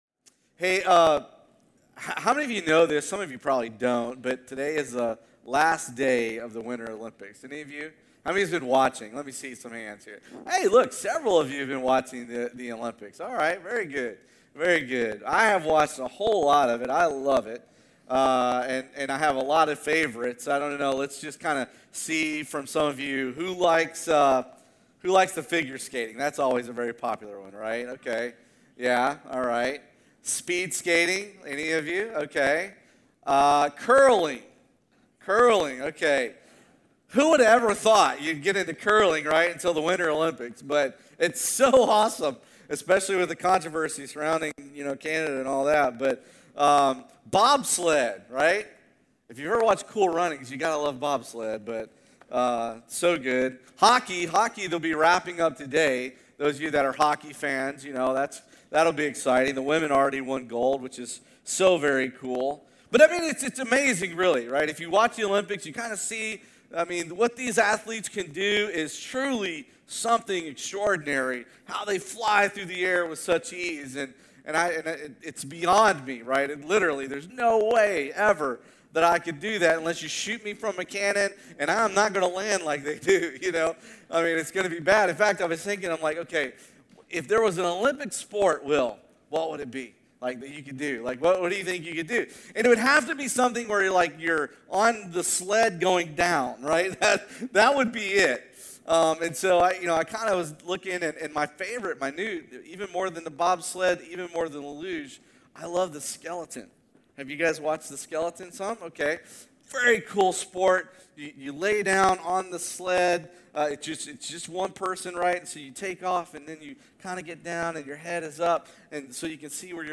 Because Jesus has already gone behind the curtain on our behalf, our hope is firm and secure. When waiting feels long and faith feels sluggish, this sermon reminds us to trust the One who never changes and never fails.